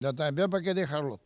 Langue Maraîchin
Patois - archives